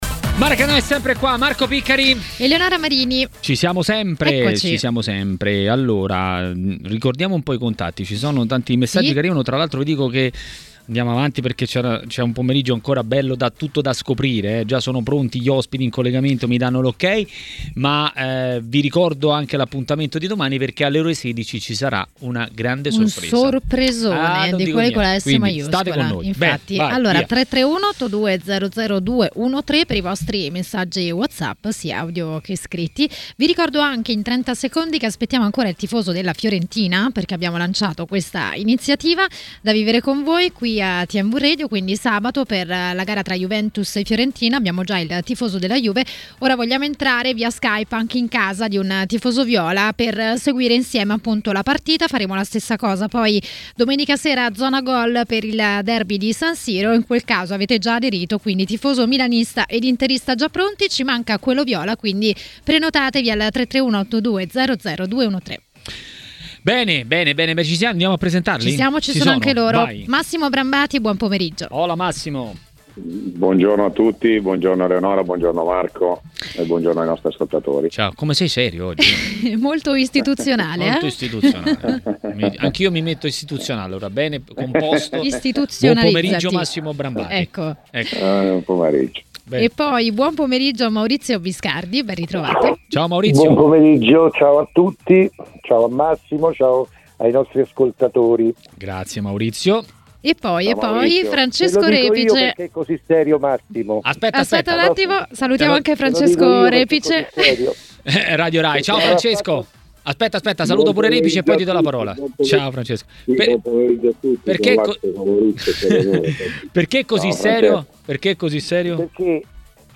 ha parlato a Maracanà, nel pomeriggio di TMW Radio, delle italiane nelle Coppe e non solo.